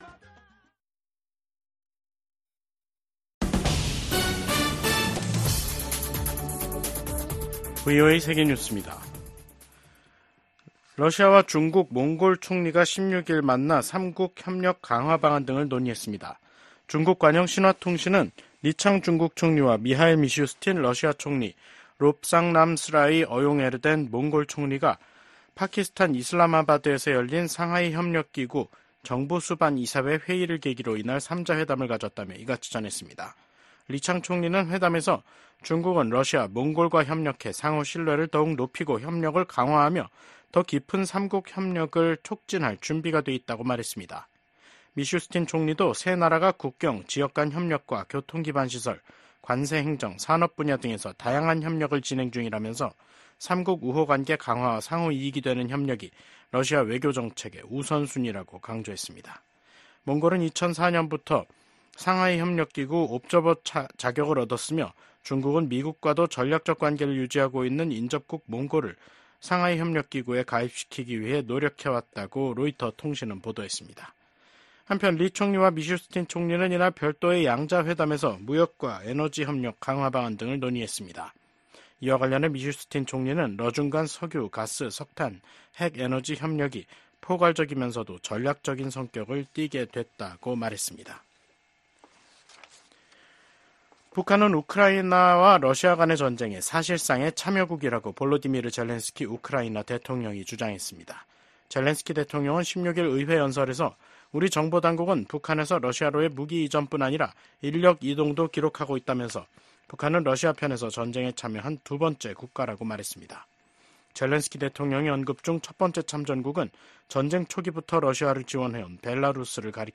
VOA 한국어 간판 뉴스 프로그램 '뉴스 투데이', 2024년 10월 16일 3부 방송입니다. 러시아의 방해로 해체된 유엔 대북 제재 감시의 공백을 메꾸기 위한 다국적 감시체제가 발족했습니다. 미국 정부는 북한이 한국과의 연결도로를 폭파하고 한국의 무인기 침투를 주장하며 군사적 대응 의사를 밝힌 데 대해 긴장 고조 행위를 멈출 것을 촉구했습니다.